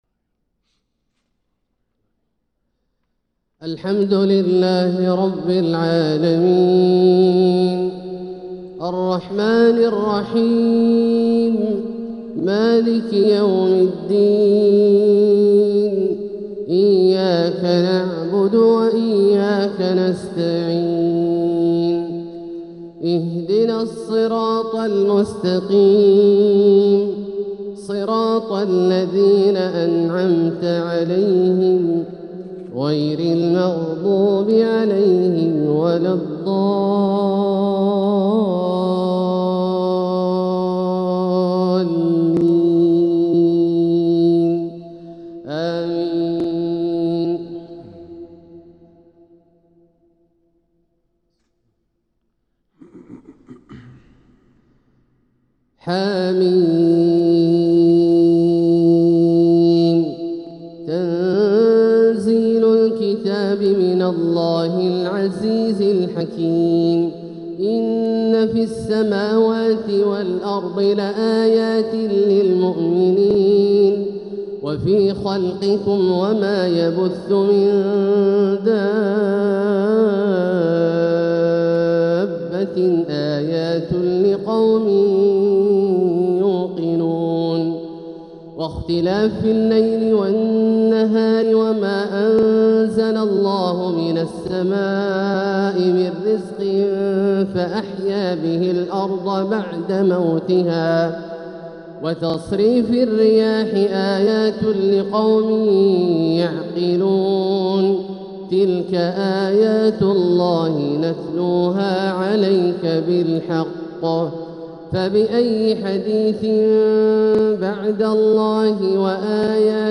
تلاوة لفواتح سورة الجاثية 1-22 | فجر السبت 3 محرم 1447هـ > ١٤٤٧هـ > الفروض - تلاوات عبدالله الجهني